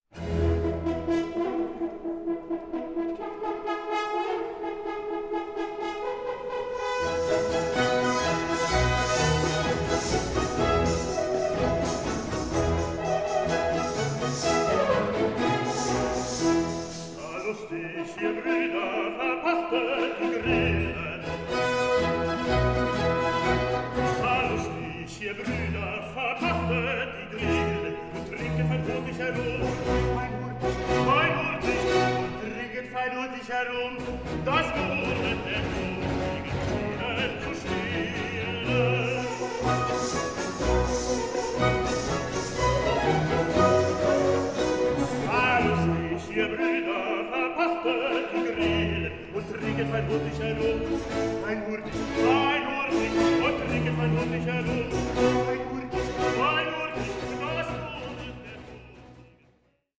ORIENTAL BAROQUE OPERA